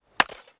Chopping and Mining
chop 3.ogg